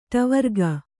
♪ ṭavarga